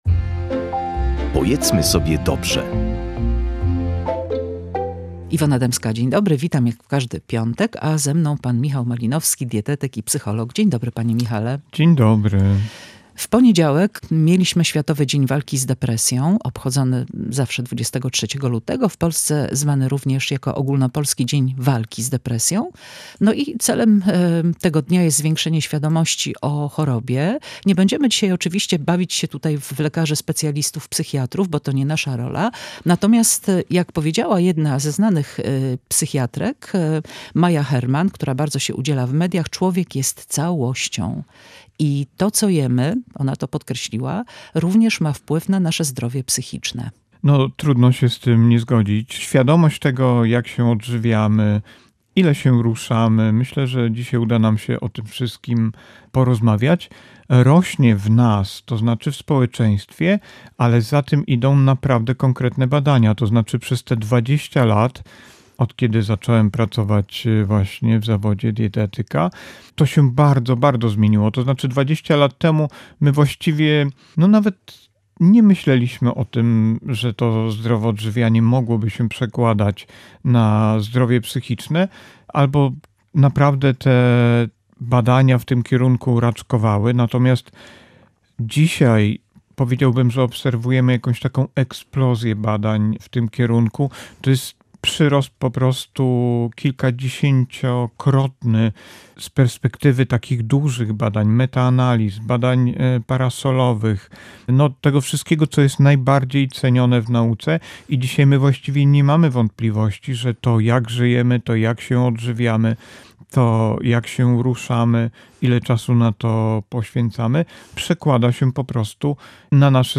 Sposób odżywiania a zdrowie psychiczne. Rozmowa o diecie chroniącej układ nerwowy